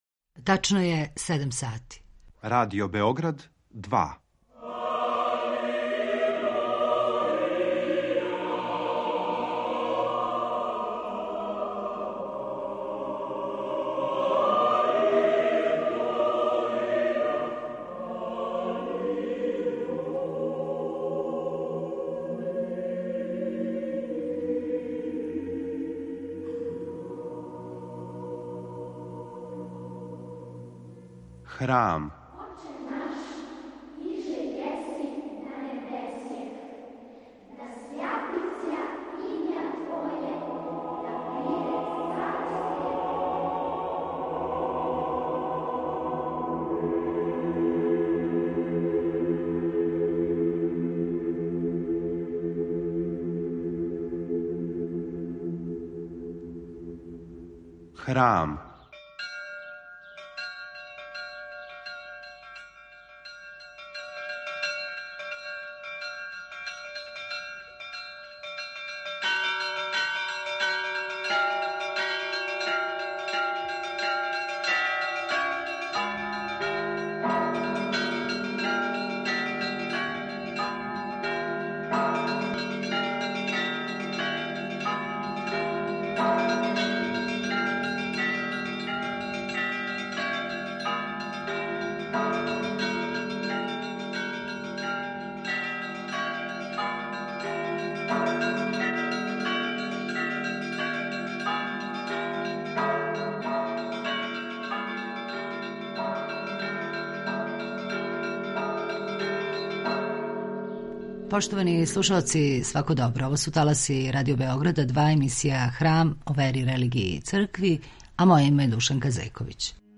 У овонедељној емисији Храм слушате излагања о очувању српских обичаја и фолклора на подручју Косова и Метохије, забележена на научном скупу у САНУ - Заштита, очување и афирмација српског културног наслеђа на Косову и Метохији.